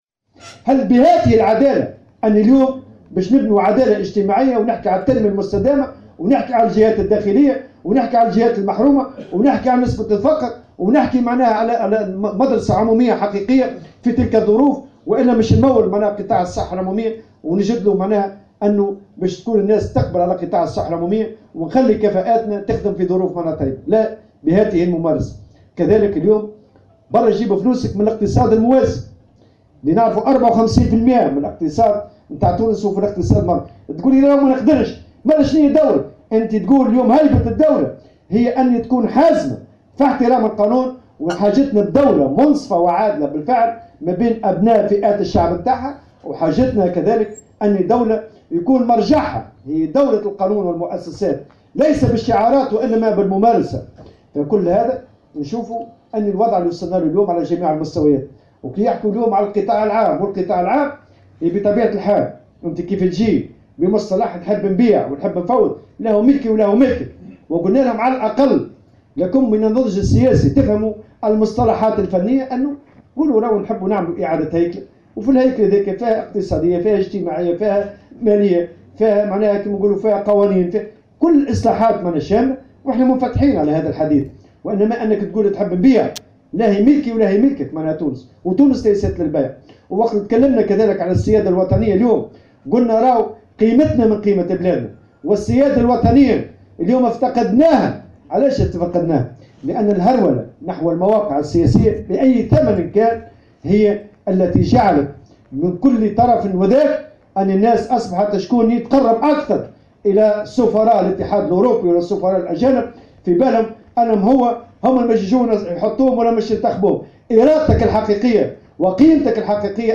قال الأمين العام للمنظمة الشغيلية نور الدين الطبوبي في تصريح لمراسلة الجوهرة "اف ام" اليوم الثلاثاء إن الوضع في تونس من سيء إلى أسوء نتيجة الخيارات السياسية الخاطئة .